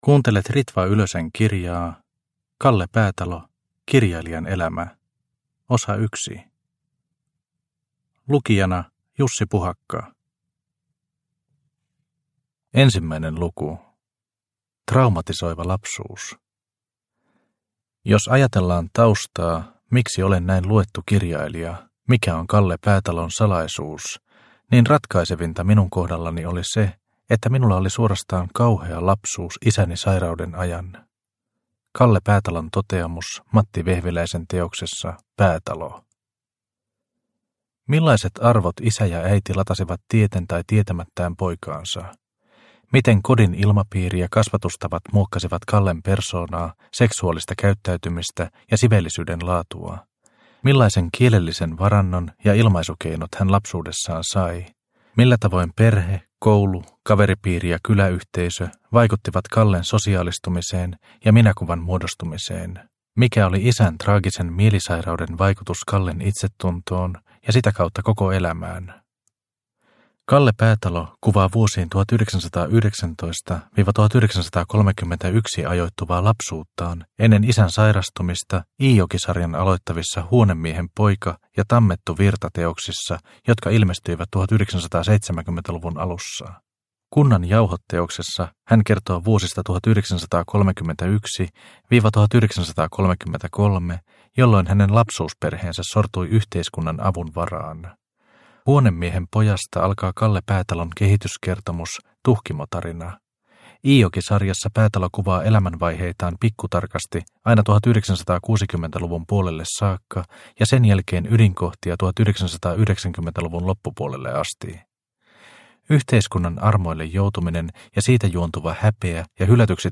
Kalle Päätalo – Ljudbok – Laddas ner